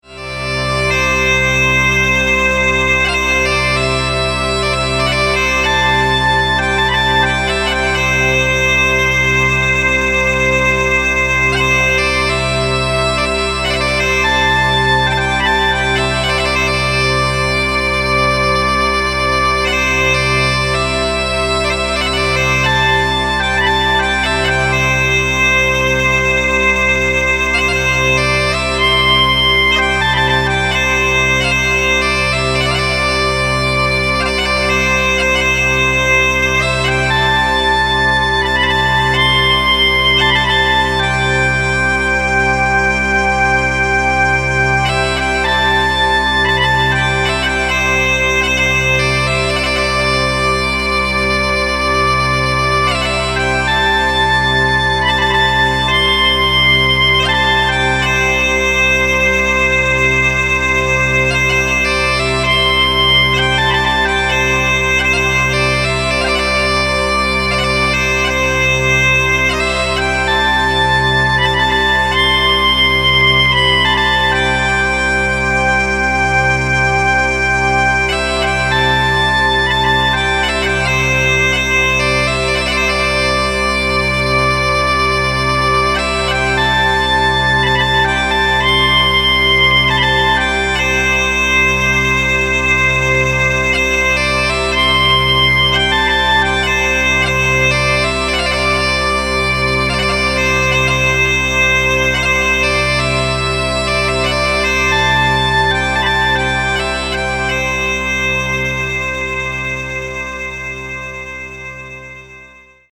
» テクノパイプの音（前半は原音、後半は環境処理した音）